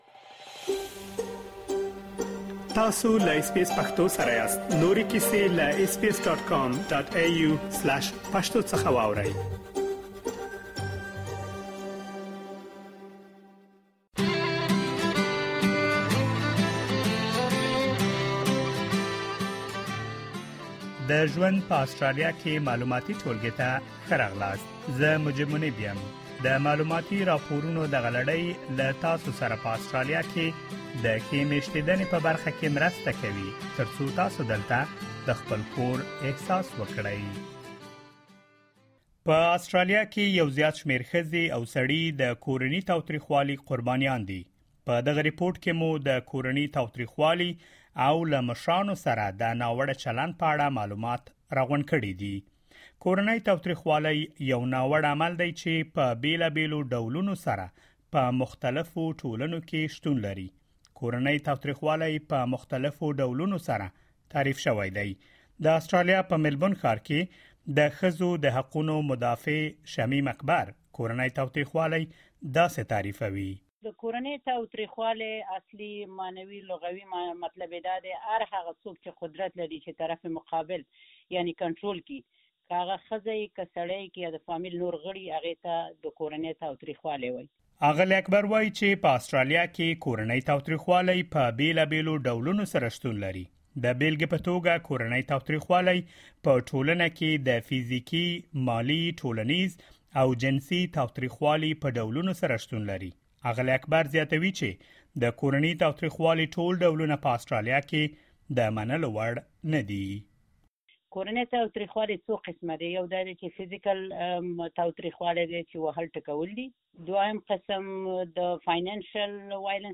In this informative report we have gathered information on recognising and stopping domestic violence and abuse of elders.